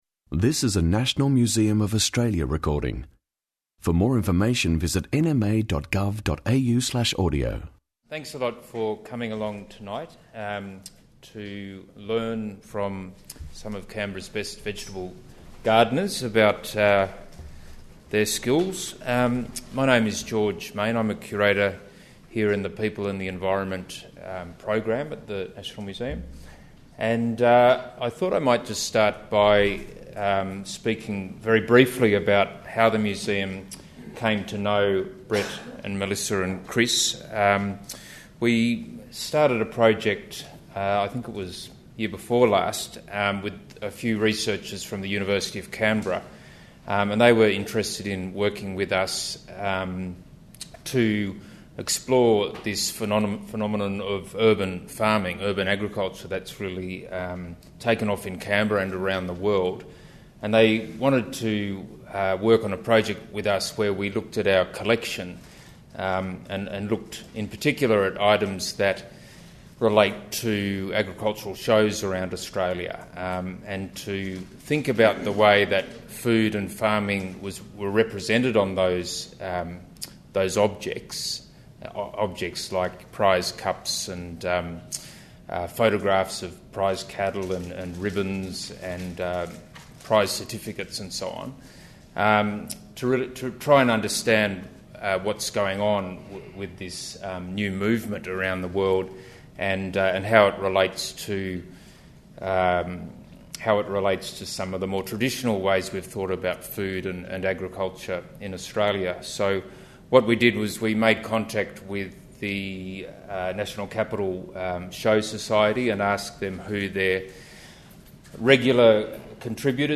To coincide with Floriade, three award-winning urban farmers give advice on how to grow award-winning produce. They explore ways in which contemporary gardeners understand and practise food production and share valuable tips and techniques.